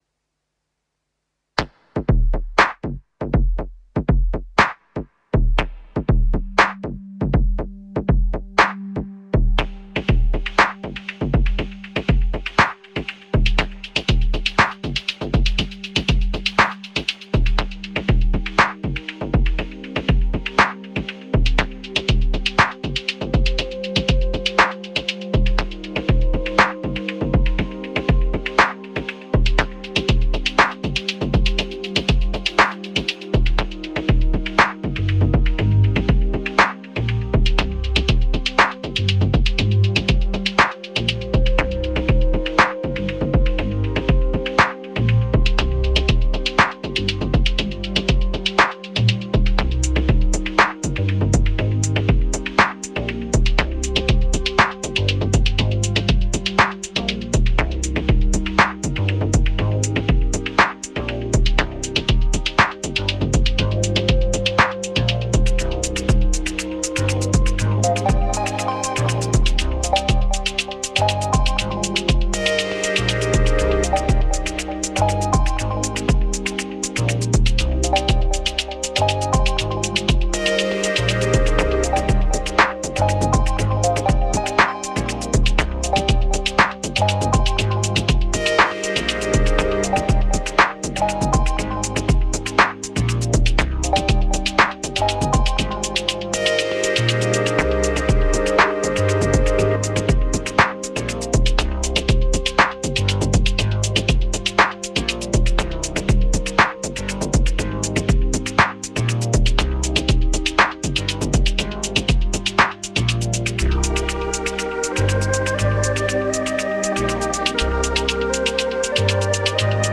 Lots of rev2 parts and analog rytm on sampling and drum duties - recorded in one take into AUM with a little compression and lots of messing about with daw cassette.